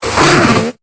Cri de Marcacrin dans Pokémon Épée et Bouclier.